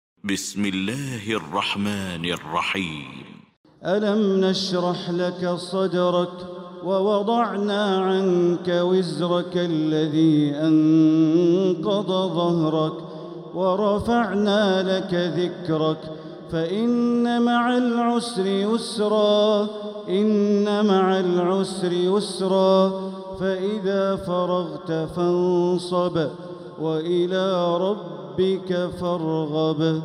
سورة الشرح Surat Ash-Sharh > مصحف تراويح الحرم المكي عام 1446هـ > المصحف - تلاوات الحرمين